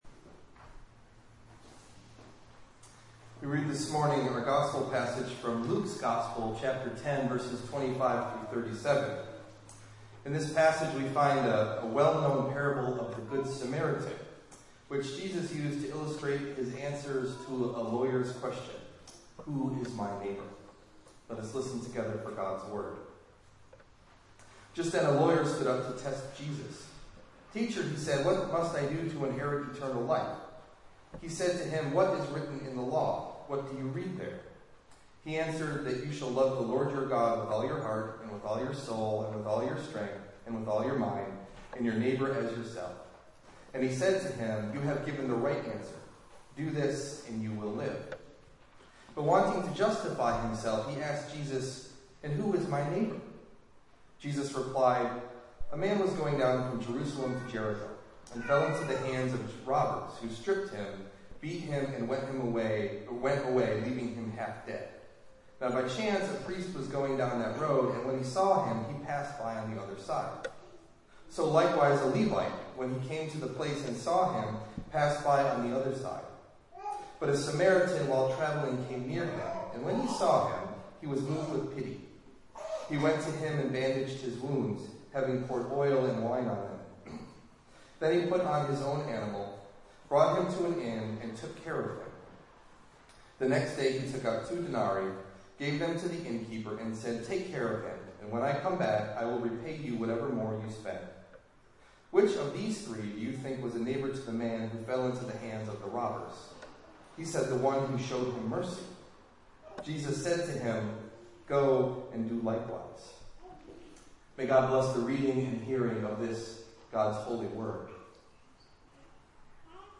Sermon: “Love’s Reach”
Delivered at: The United Church of Underhill